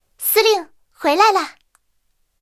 I号战斗返回语音.OGG